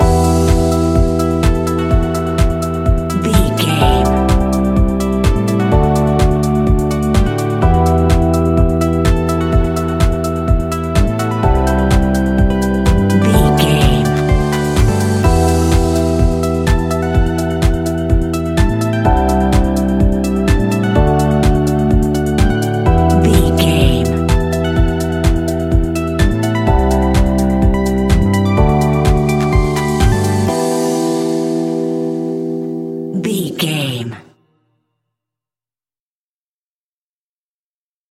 Aeolian/Minor
uplifting
energetic
bouncy
funky
bass guitar
synthesiser
electric piano
drum machine
funky house
groovy
instrumentals